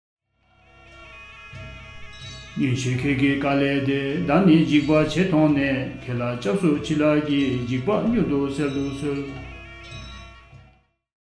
Karma_BCA_Chapter_2_verse_53_with_music.mp3